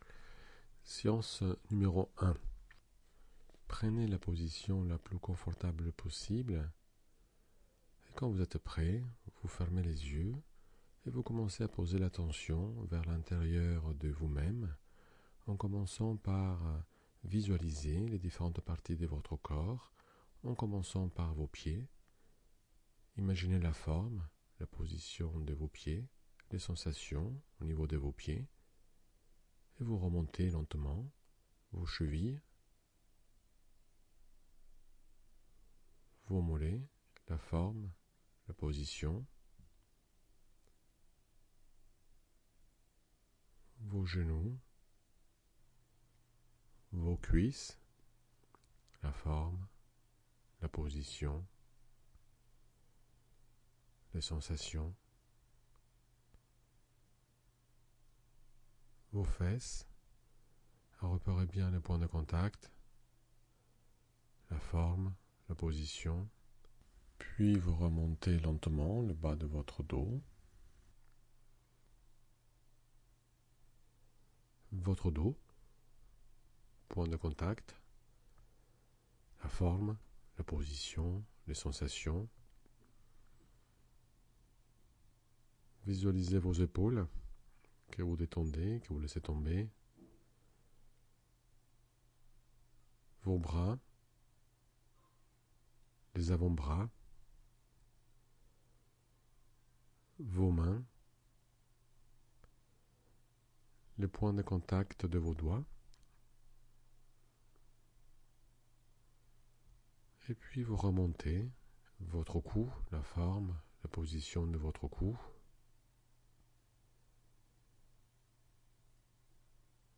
Relaxation de Jacobson audio cliquer sur le titre :